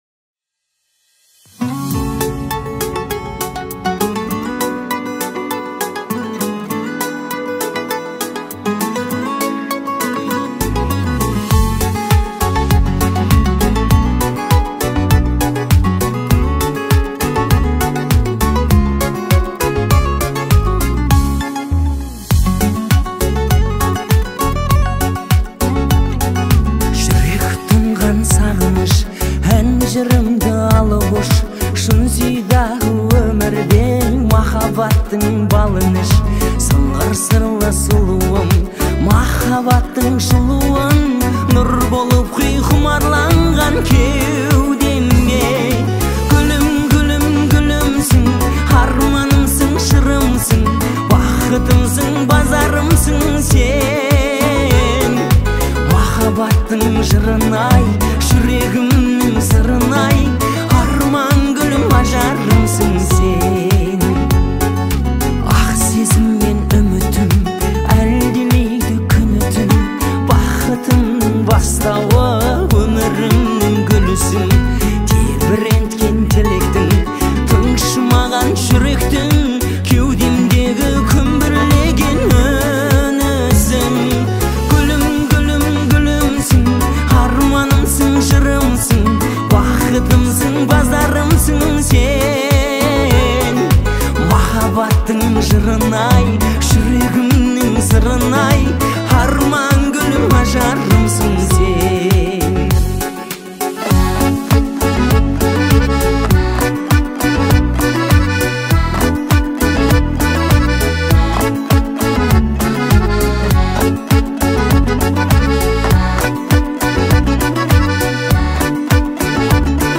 Музыка сочетает в себе мелодичность и лёгкость